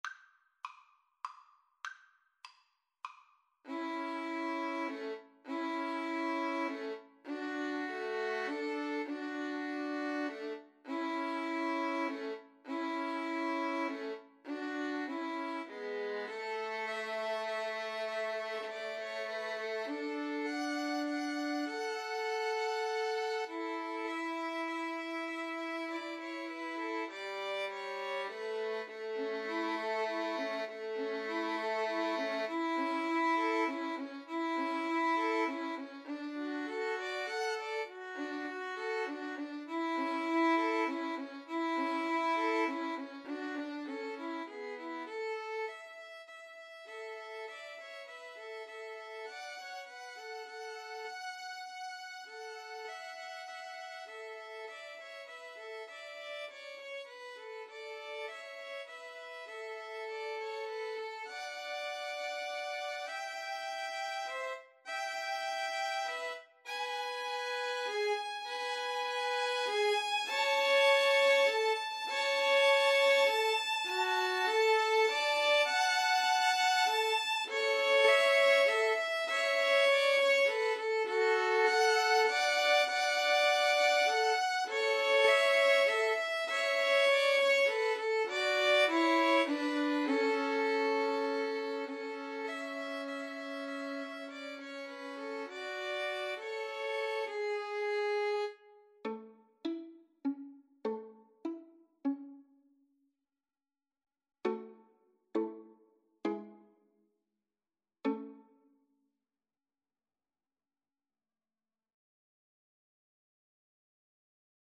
A major (Sounding Pitch) (View more A major Music for 2-Violins-Cello )
Gently = c. 100
Traditional (View more Traditional 2-Violins-Cello Music)